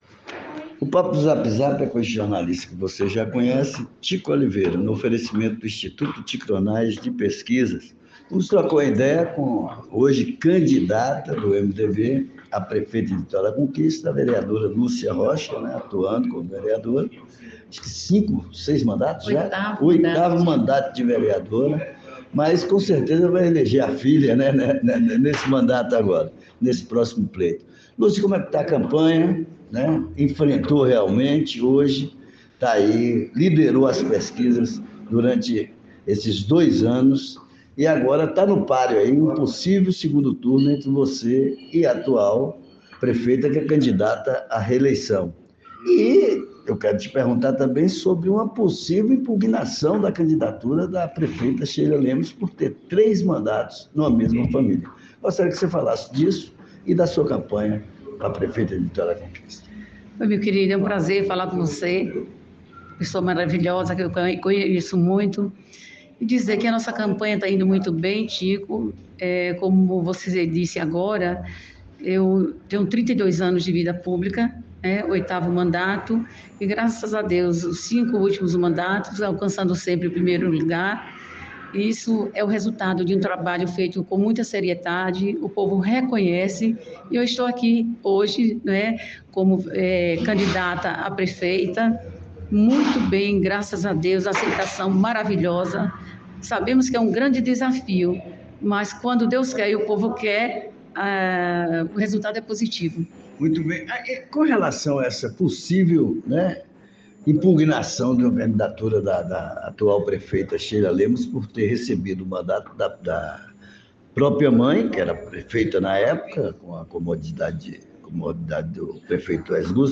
A vereadora Lúcia Rocha (MDB) de oito (8) mandatos eletivos no legislativo municipal e agora candidata a prefeita, trocou uma ideia impactante no papo do ZAP ZAP.